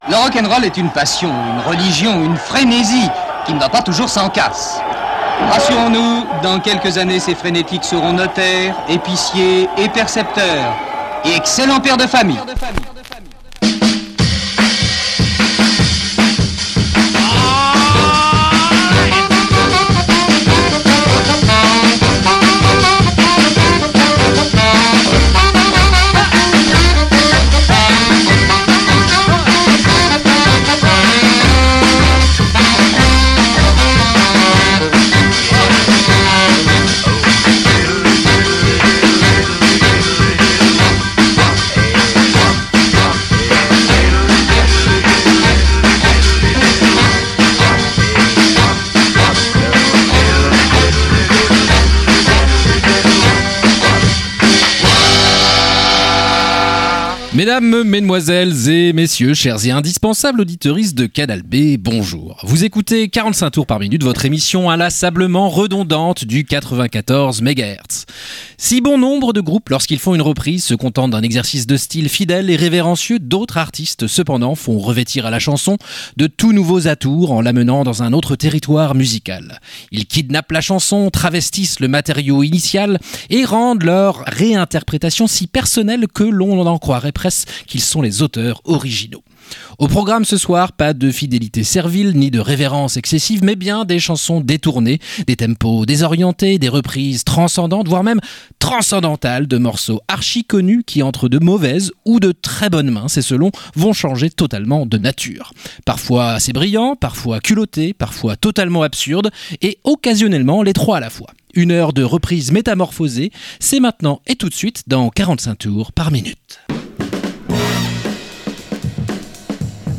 Pour la dernière chanson la fin a été coupée